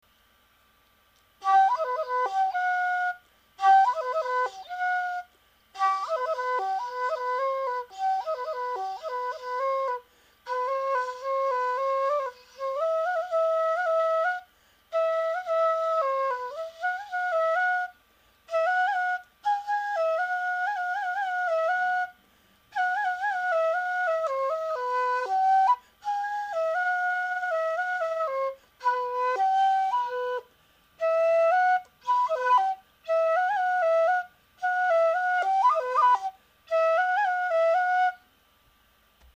笛は未熟者で恐縮ですが、私の笛を録音させていただきました。
笛のメロディ